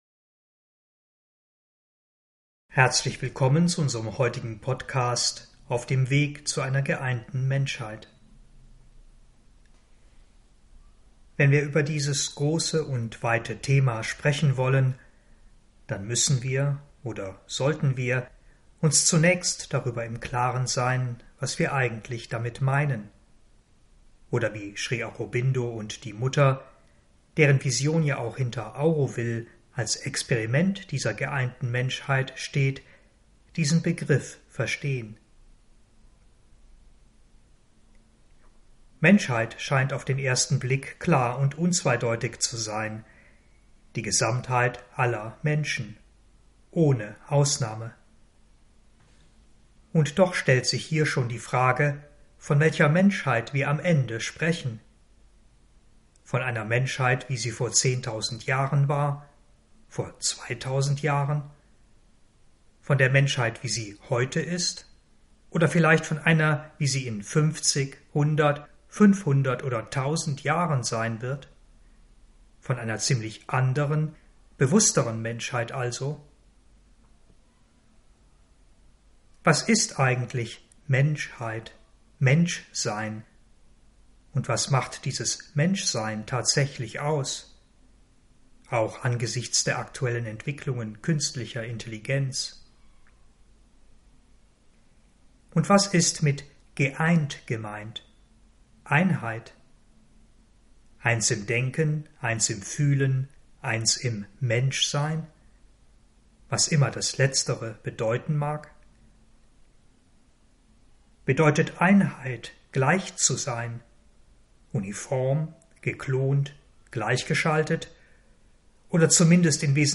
Texte und Vorträge
Vortrag_geeinte_Menschheit.mp3